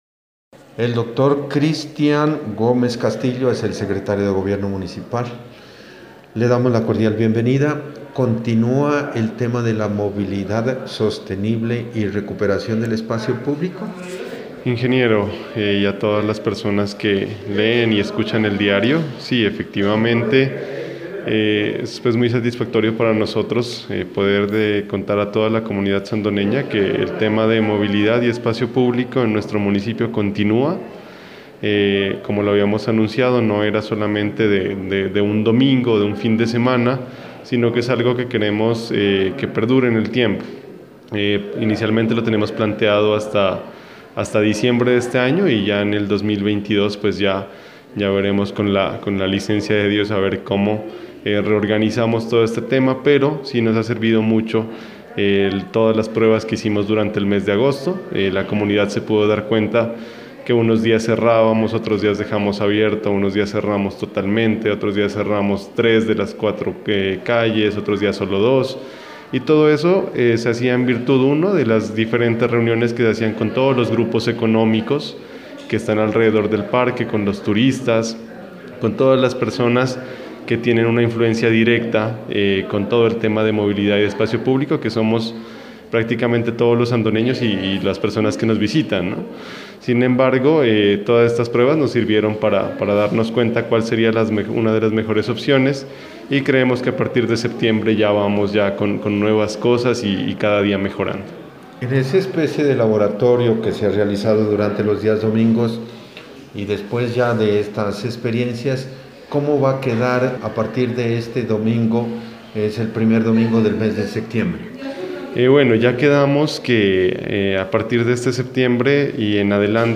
Continúa el programa de movilidad y espacio público (entrevista)